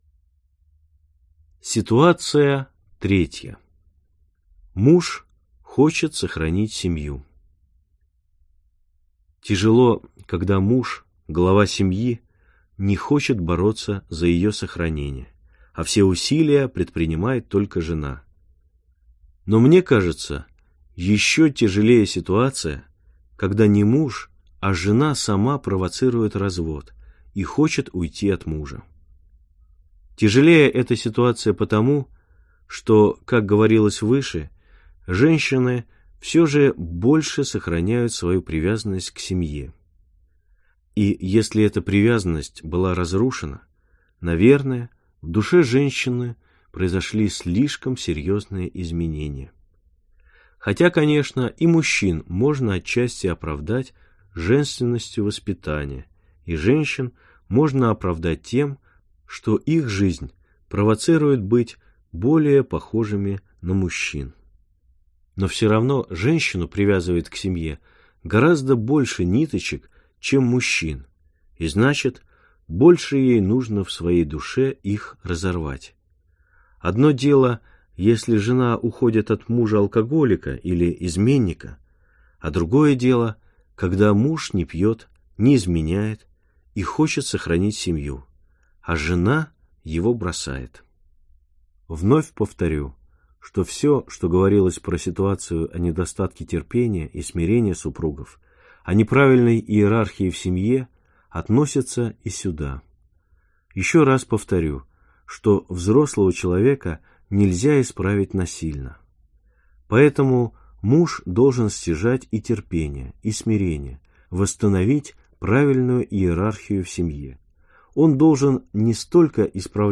Аудиокнига Как сохранить семью | Библиотека аудиокниг
Прослушать и бесплатно скачать фрагмент аудиокниги